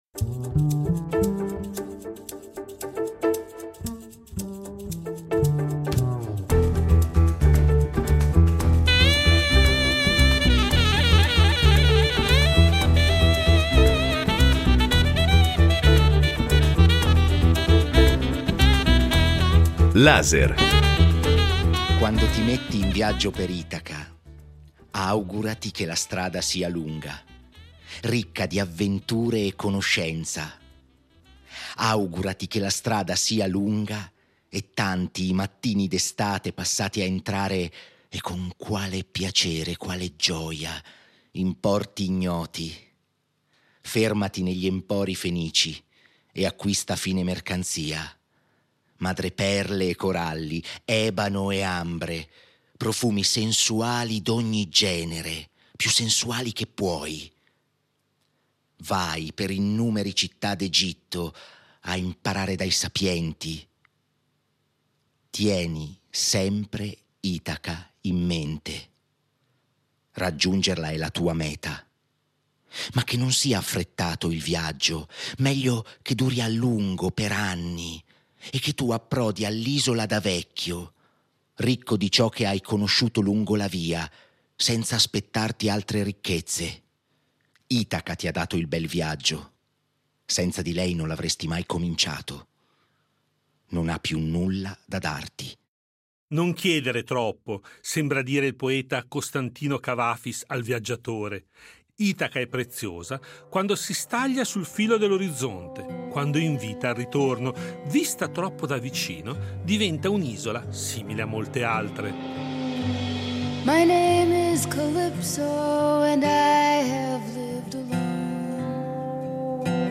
Per questo, lasciando da parte per un momento il suo straordinario passato, in questo viaggio estivo abbiamo cercato semplicemente di raccontarla nella sua vita quotidiana, alternando paesaggi sonori e incontri.